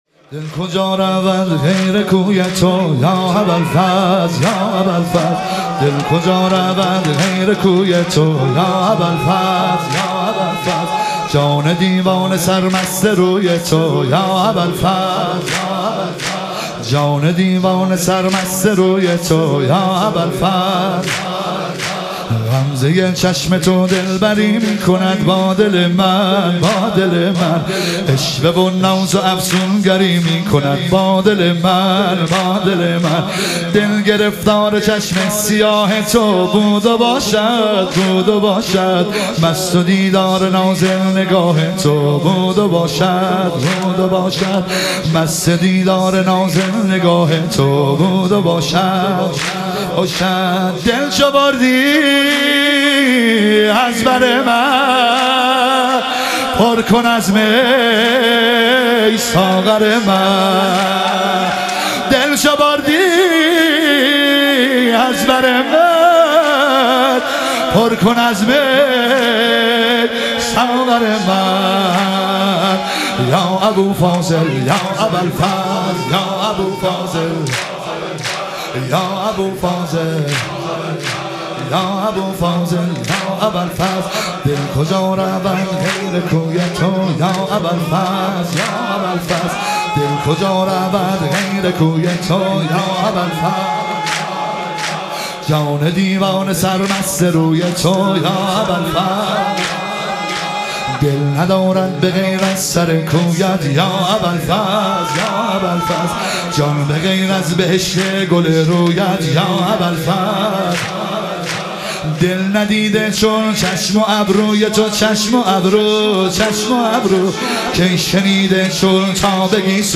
شهادت امام صادق علیه السلام - واحد